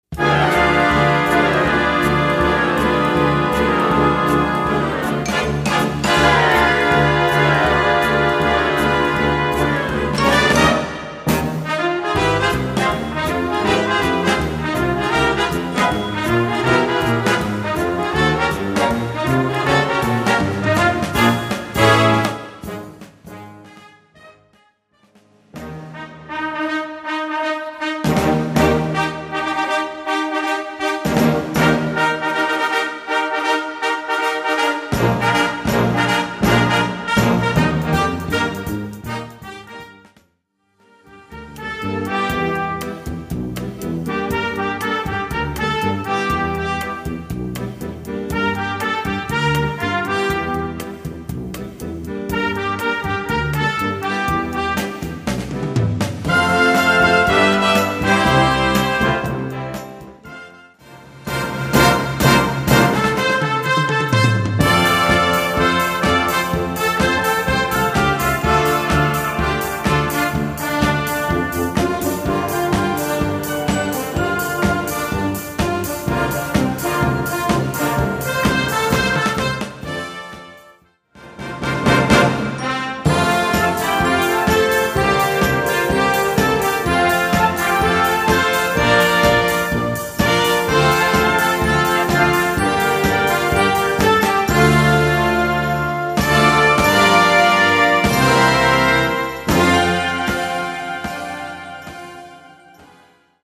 Répertoire pour Harmonie/fanfare - Concert Band Ou Harmonie